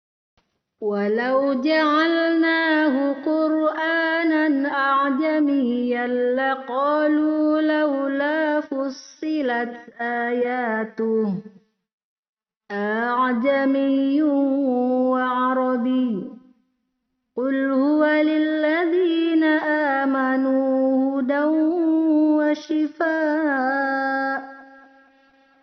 Tashil yaitu cara membaca hamzah yang kedua dengan suara ringan, terdapat dalam surah Fusshilat ayat 44.